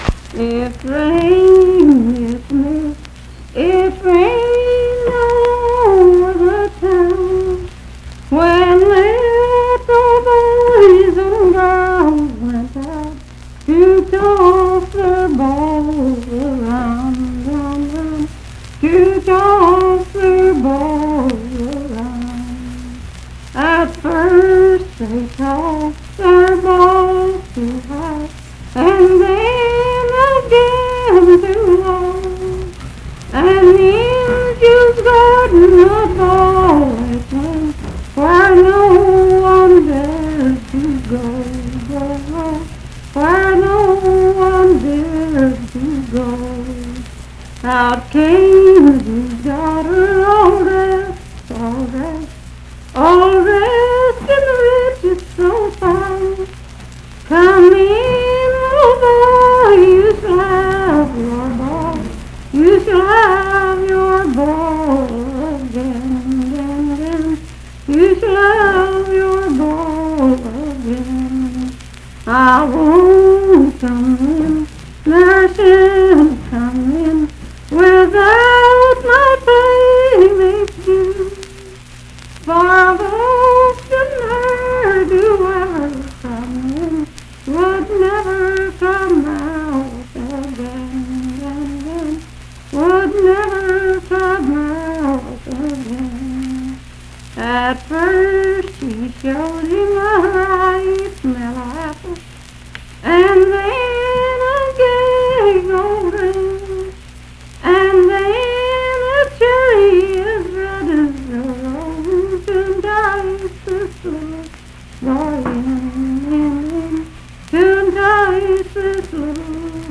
Unaccompanied vocal music
Verse-refrain 8(5w/R).
Voice (sung)